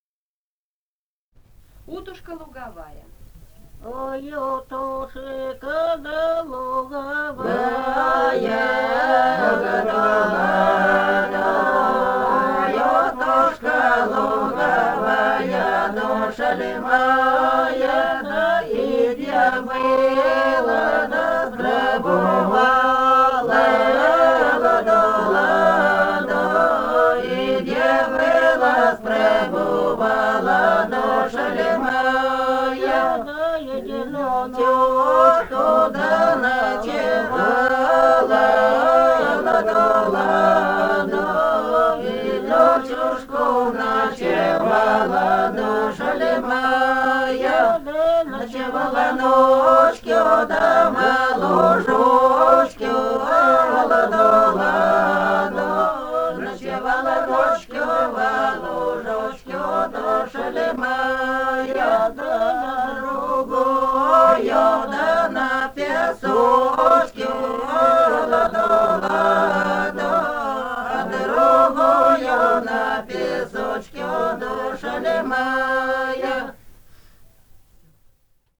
Этномузыкологические исследования и полевые материалы
«Ой, утушка да луговая» (плясовая).
Алтайский край, с. Михайловка Усть-Калманского района, 1967 г. И1001-15